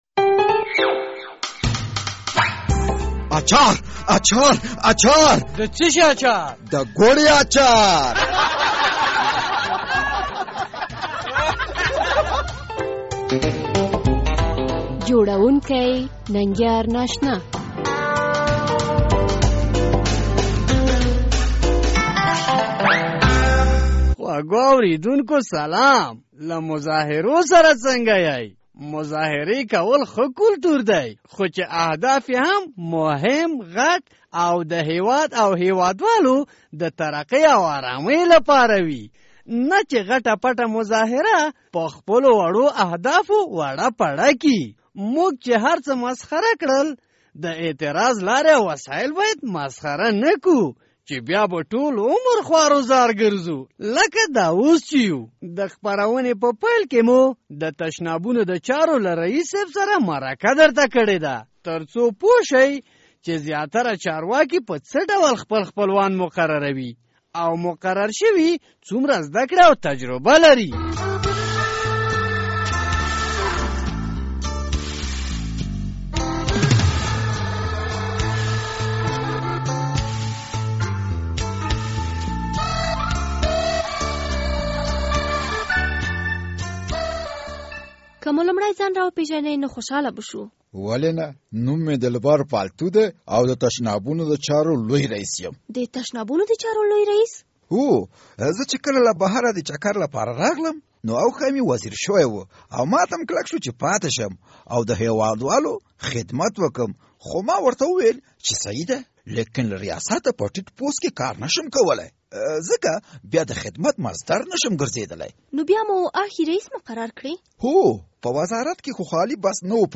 د ګوړې اچارپه دې خپرونه کې لومړی د تشنابونو د چارو له لوی رییس صیب سره مرکه درته لرو.